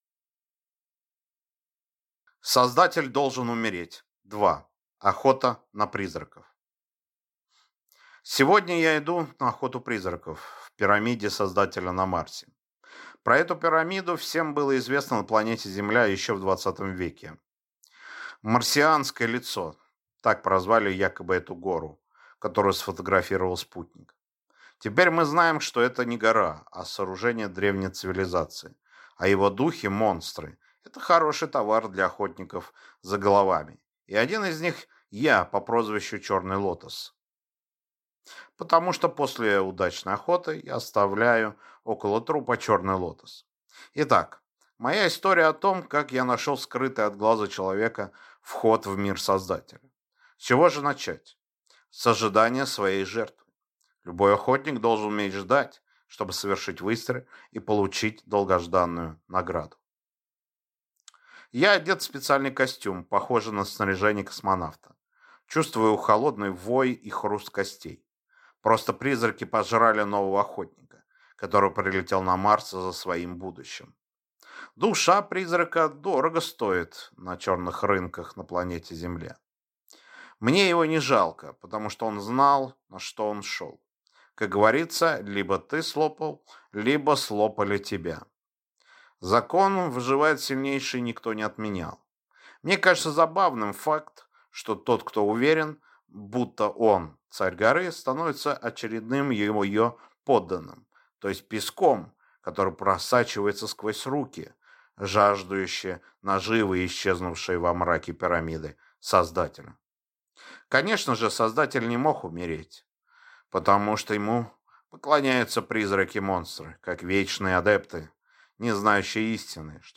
Аудиокнига Создатель должен умереть – 2: Охота на призраков | Библиотека аудиокниг